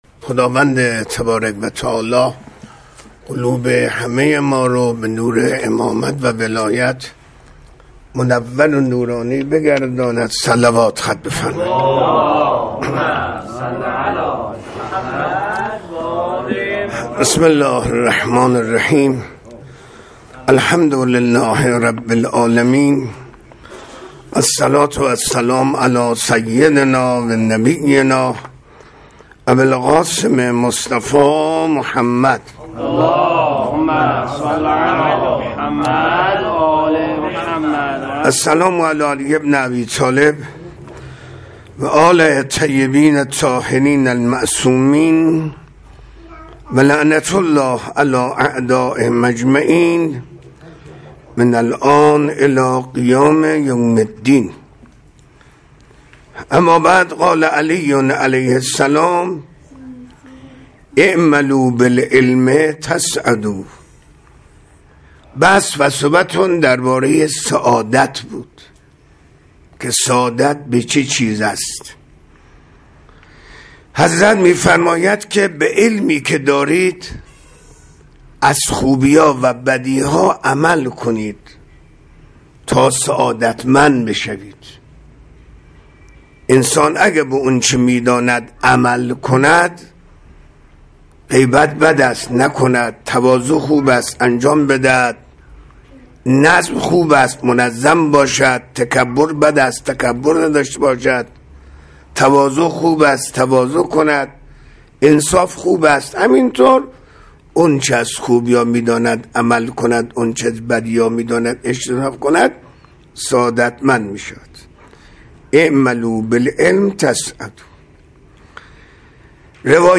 سخنرانی
مکان: مسجد جوادالائمه (ع) مناسبت: فاطمیه